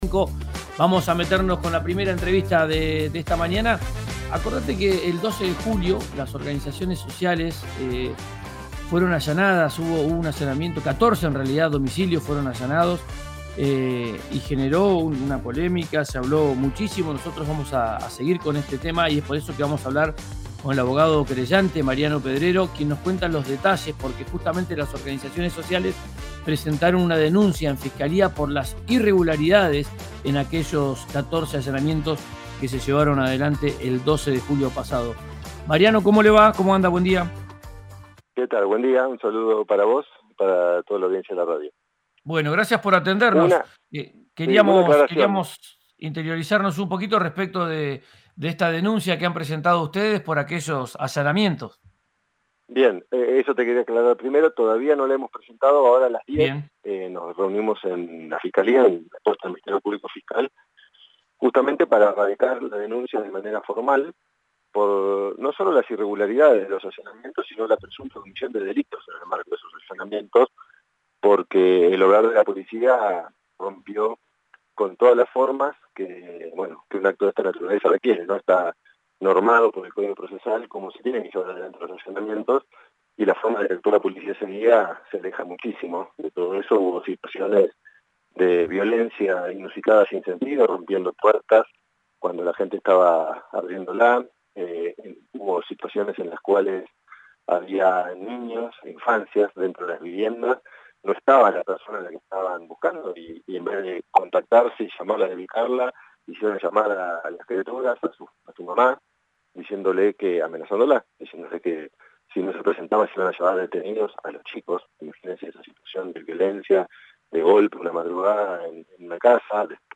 en RÍO NEGRO RADIO.